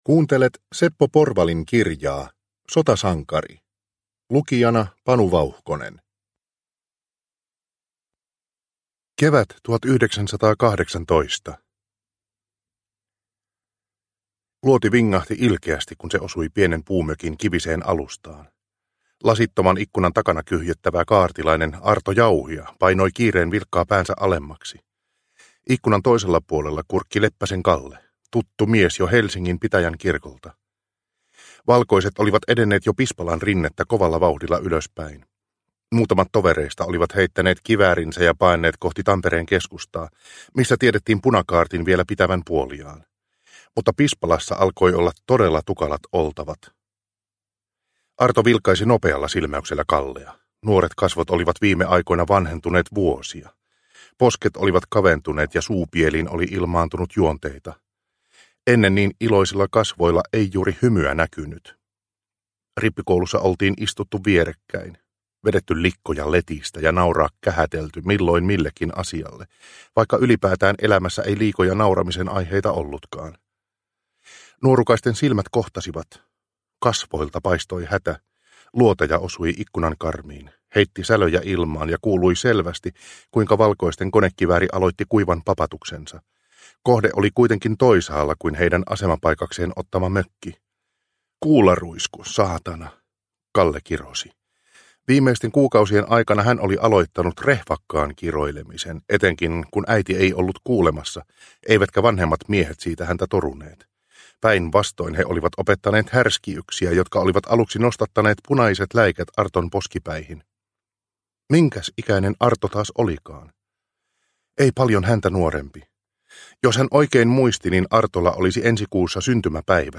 Sotasankari – Ljudbok – Laddas ner